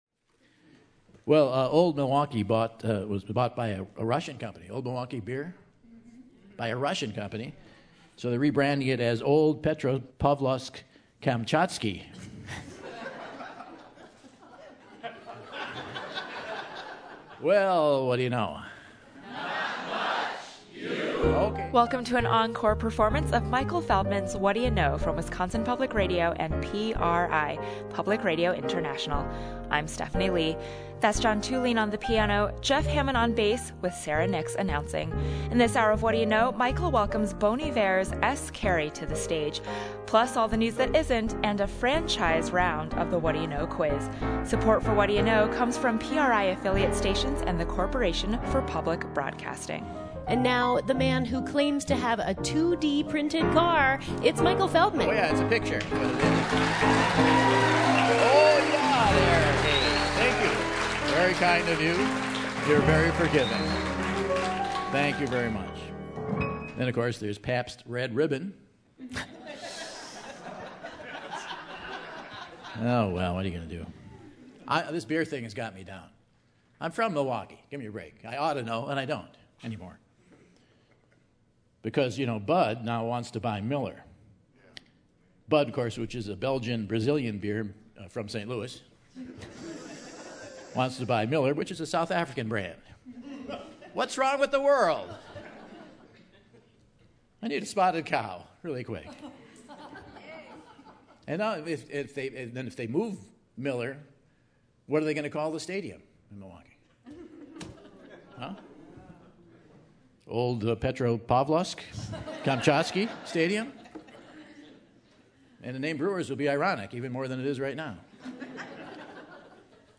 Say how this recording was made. October 31, 2015 - Madison, WI Monona Terrace - Rebroadcast | Whad'ya Know?